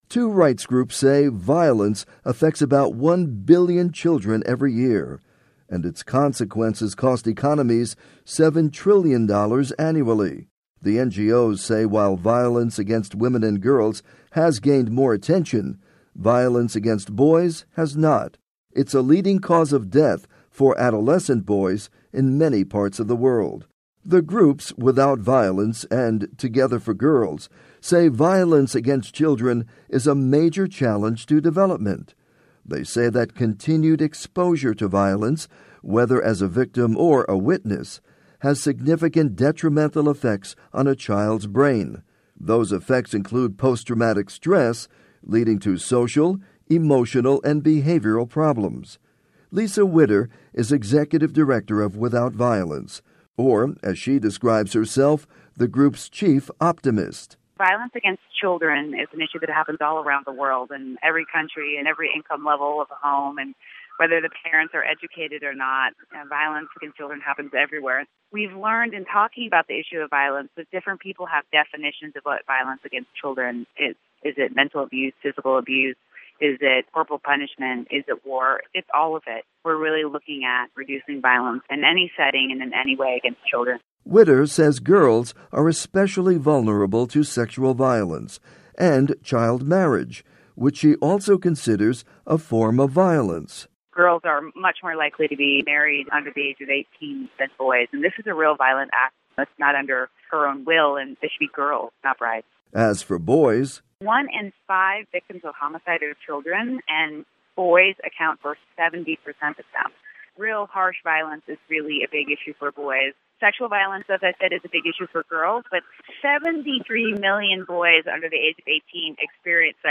report on violence against children